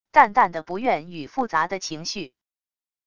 淡淡的不愿与复杂的情绪wav音频